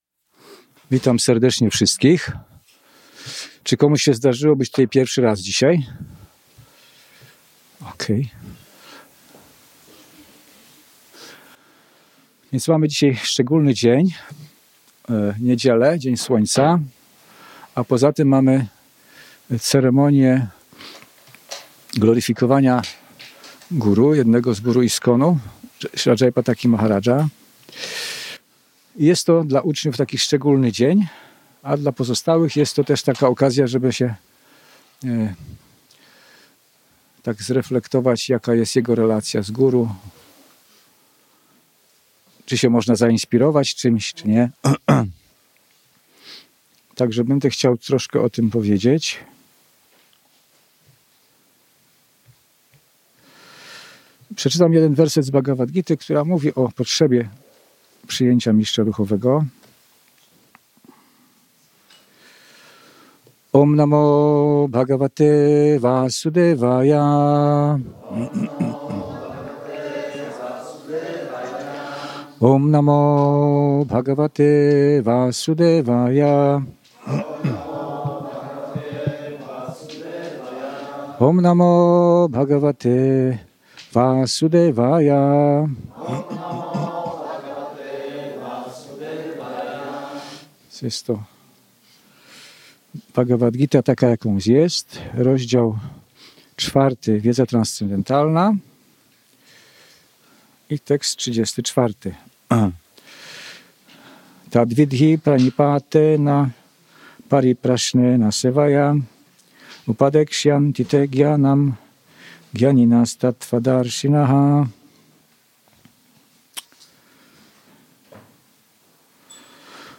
Wykład na bazie Bhagawadgity 4.34 wygłoszony 12 kwietnia 2026 roku.